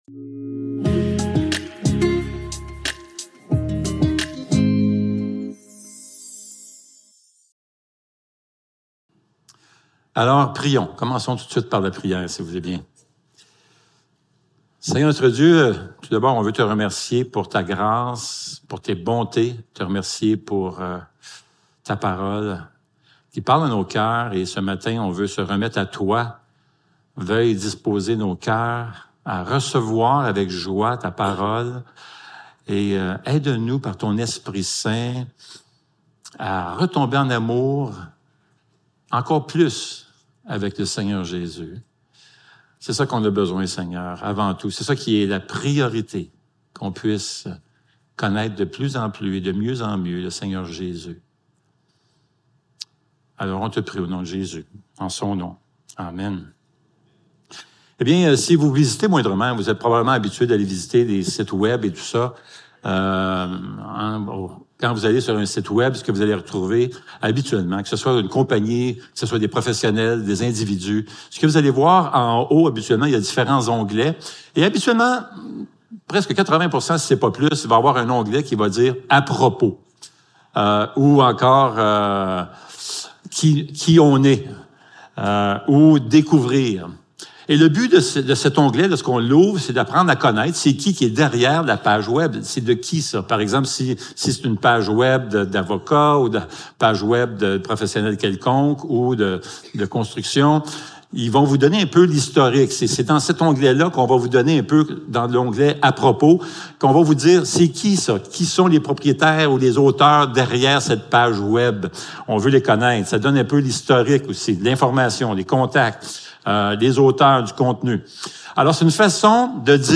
Célébration dimanche matin